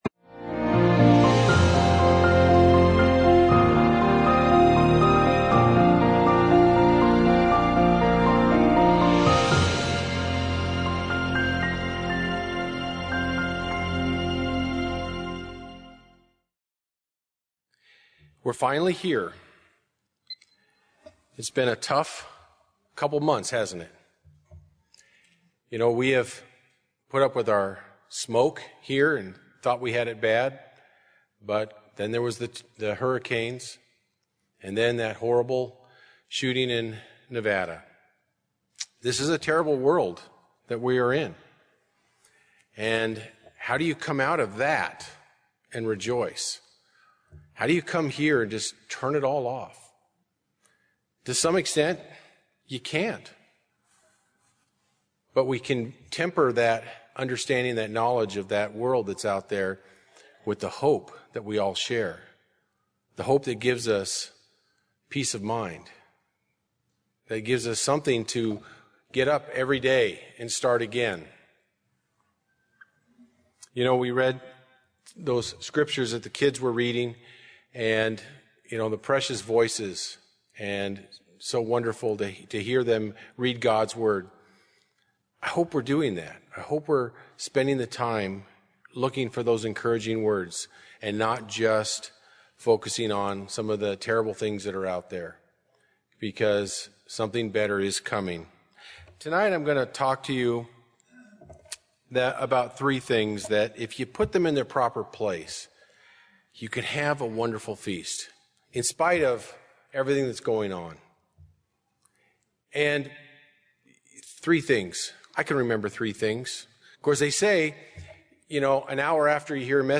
This sermon was given at the Bend-Redmond, Oregon 2017 Feast site.